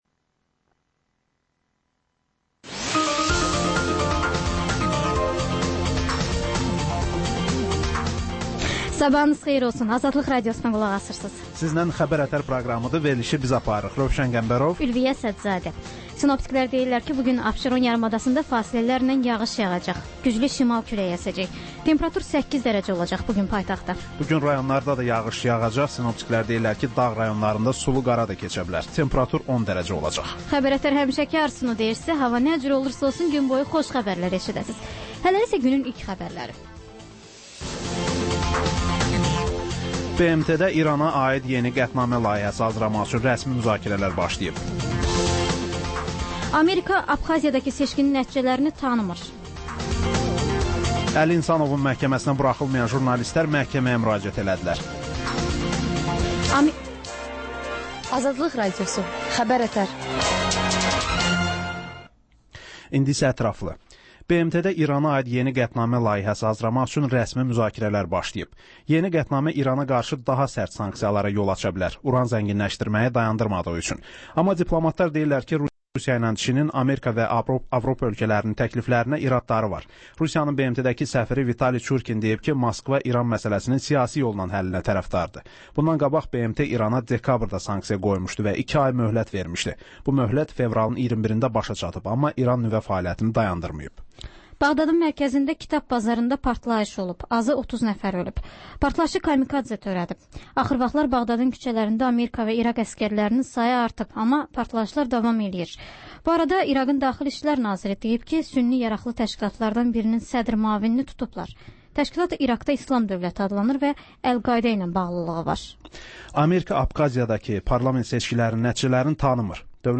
Səhər-səhər, Xəbər-ətər: xəbərlər, reportajlar, müsahibələr. Hadisələrin müzakirəsi, təhlillər, xüsusi reportajlar. Və sonda: Azərbaycan Şəkilləri: Rayonlardan reportajlar.